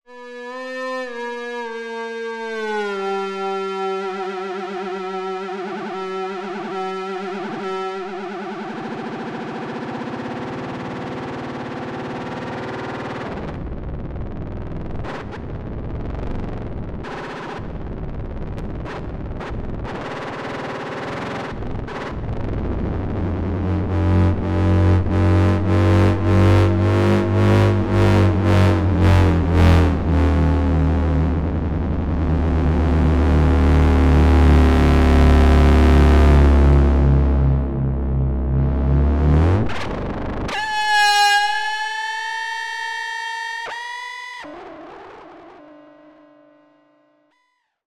No other effects processing was used other than some normalization, and each example is a single track. All effects and pitch modulation were improvised using the FLUX bows.